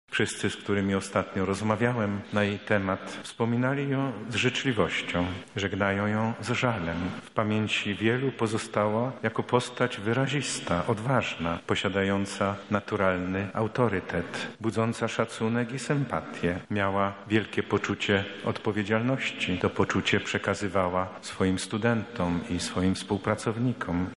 W Świdniku pożegnano prof. Zytę Gilowską
Odejście Pani Profesor wywołało wielkie poruszenie w wielu środowiskach – mówił podczas kazania arcybiskup Stanisław Budzik, metropolita lubelski.